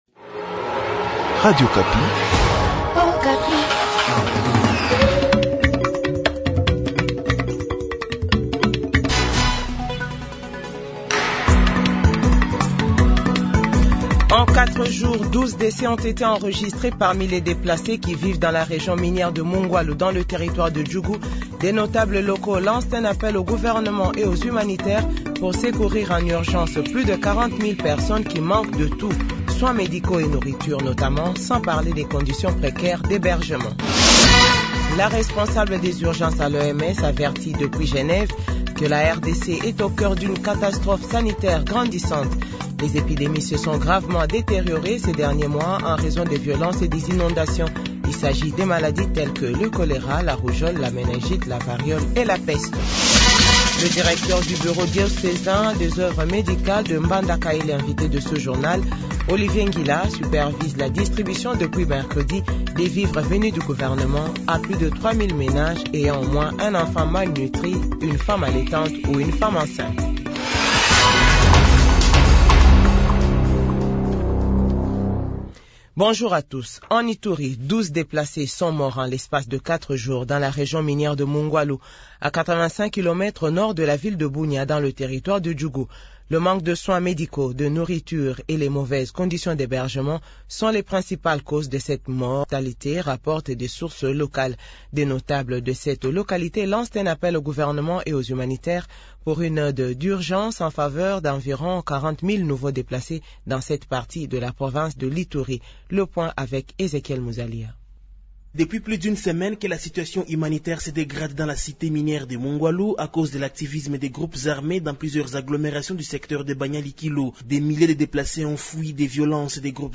JOURNAL DE FRANCAIS DE 7H00 - 8H00